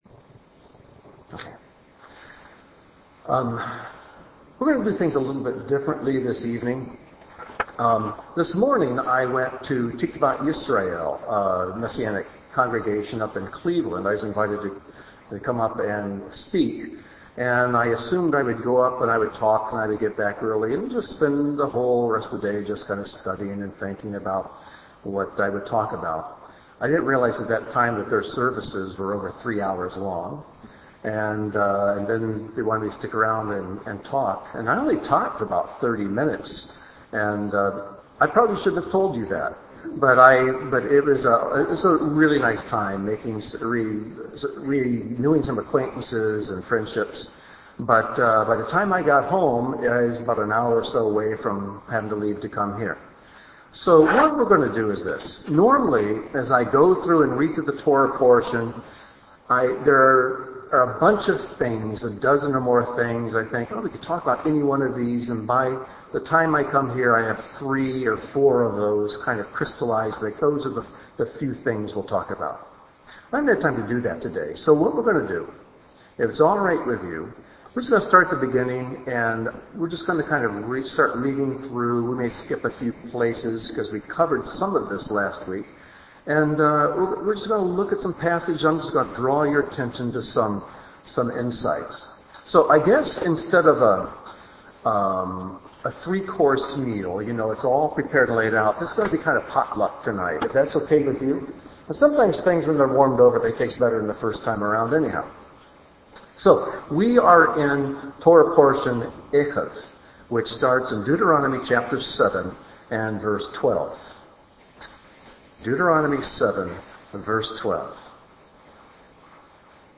This teaching’s study Resources: There are no visuals for this teaching.